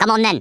Worms speechbanks
comeonthen.wav